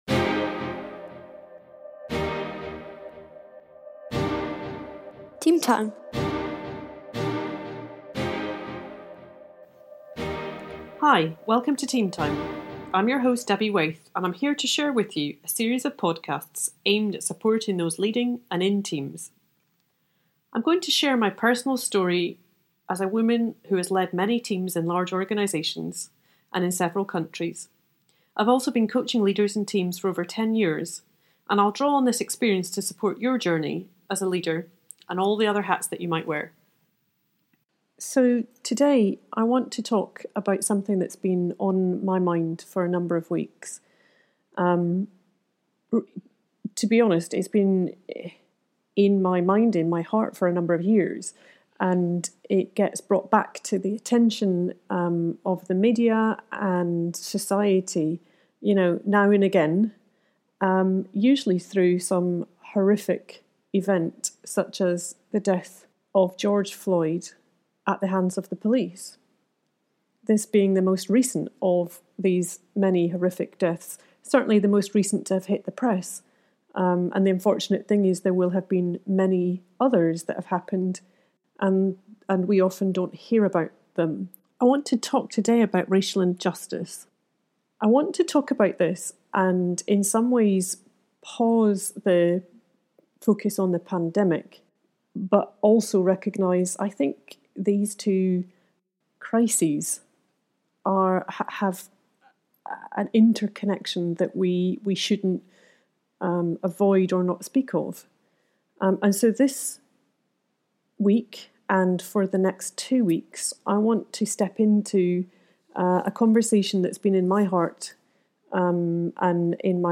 Recognising the power of labels and shame in getting stuck in this hugely important conversation, I share both how I am feeling and thinking as a white woman. I’ll explore the important distinction I’ve learned between being ‘not racist’ and ‘anti-racist’, and some of the symbols that exemplify this distinction.